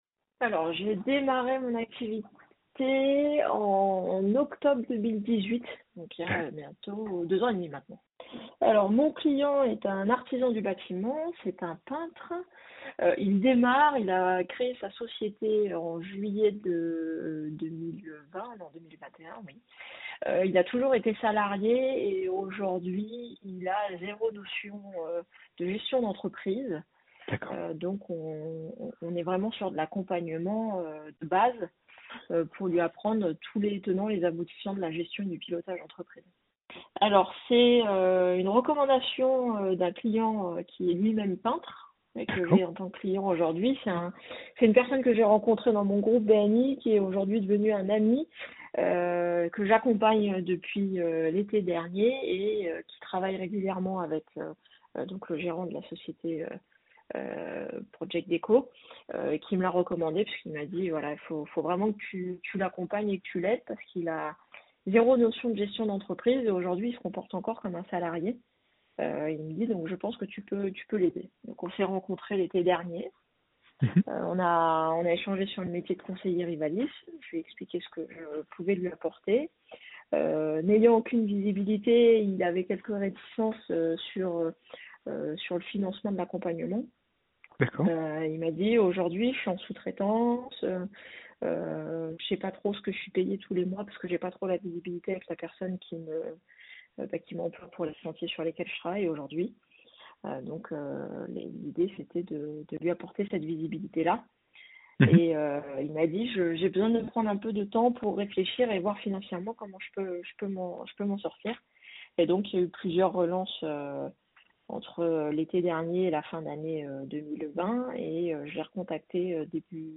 Ecoutez son témoignage audio en cliquant ci-dessous :